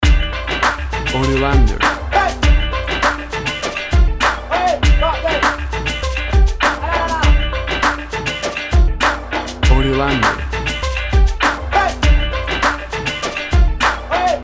Loop R & B with emphasis on danceable rhythm.
Tempo (BPM) 100